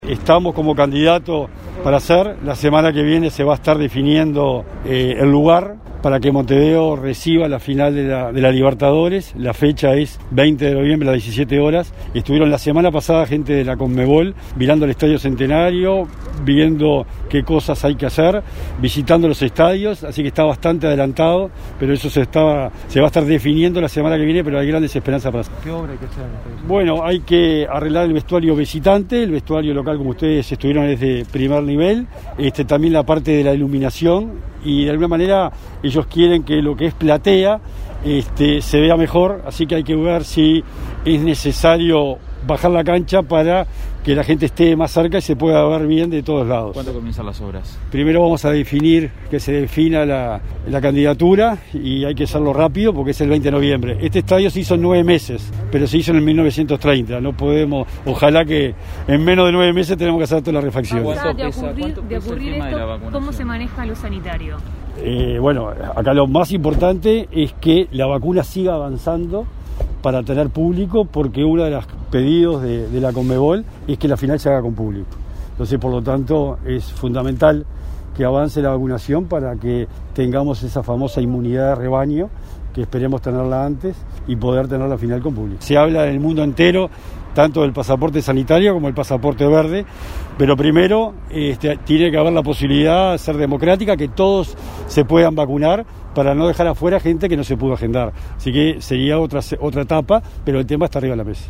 “La semana pasada una delegación de la Conmebol estuvo mirando el Estado Centenario y viendo qué obras hay que hacer. La semana que viene se definirá pero hay grandes esperanzas” afirmó Bauzá en rueda de prensa.